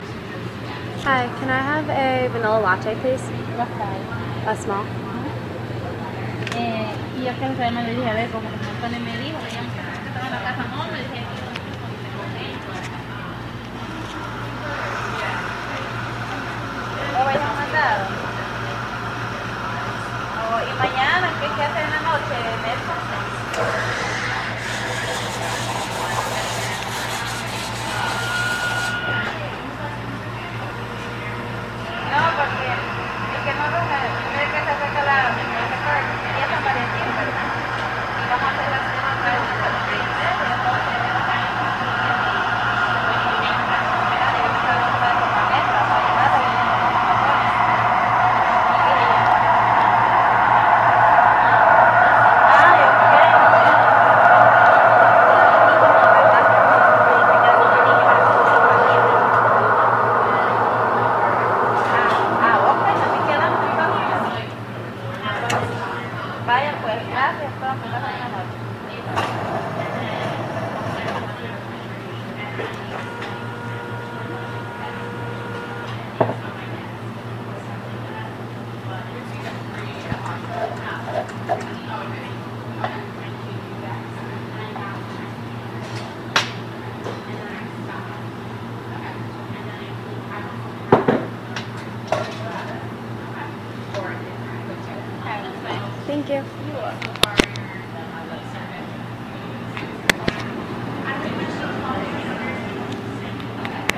Espresso Machine – Hofstra Drama 20 – Sound for the Theatre
Location: Hofstra University Starbucks Cafe
–the distant echo of chatter coming from the student center
Location: Hofstra University Starbucks Cafe Sounds to be heard: –the distant echo of chatter coming from the student center — steam spurting from the espresso machine — coffee beans grinding in the coffee machine — a stool scraping across the floor as someone gets up from their seat.